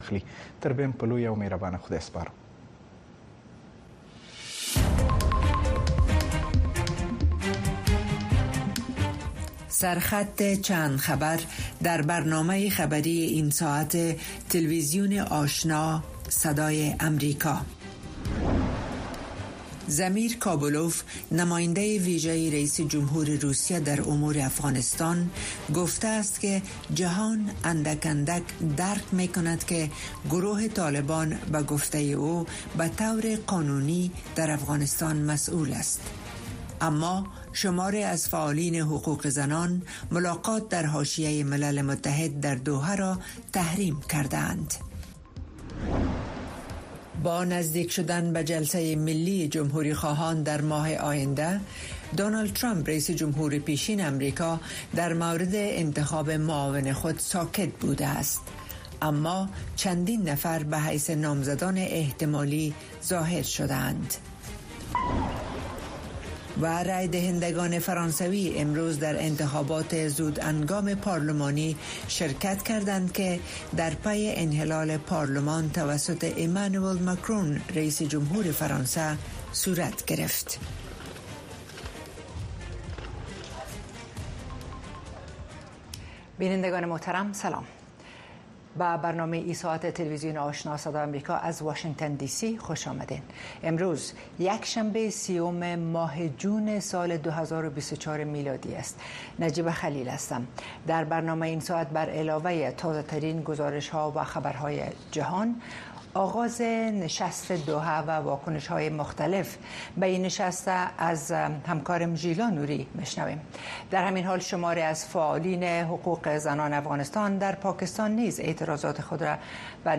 برنامه خبری آشنا